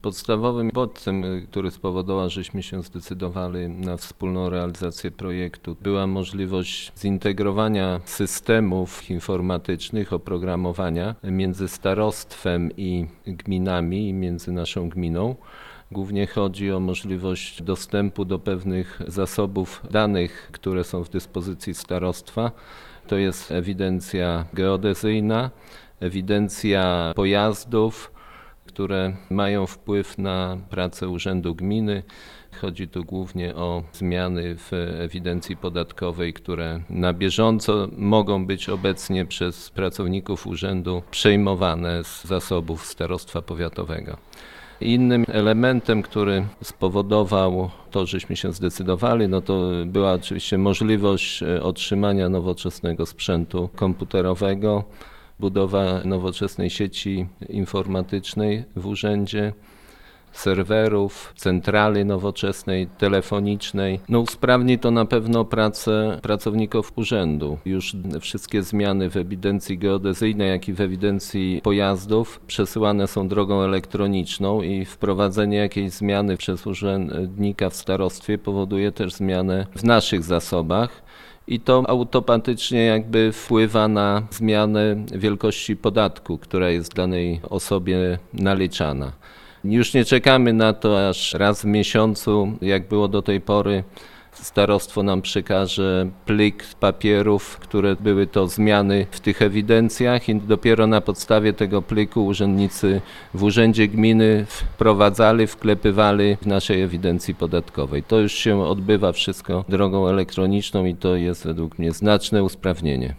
Wójt Jacek Anasiewicz tłumaczy, że powodów takiej decyzji było wiele, wśród nich dwa są najważniejsze: